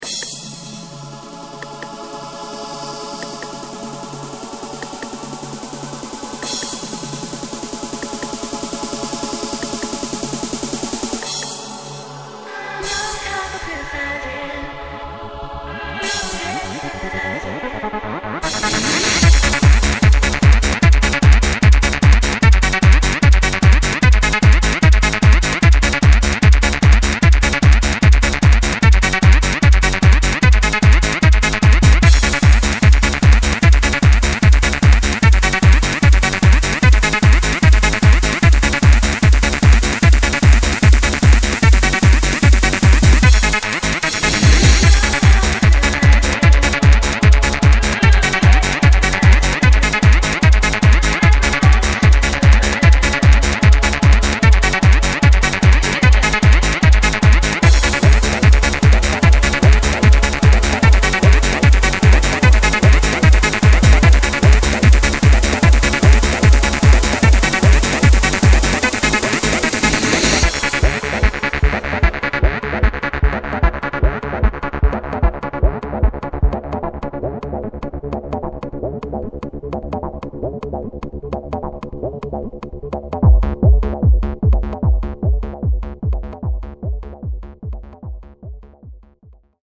Hard-Trance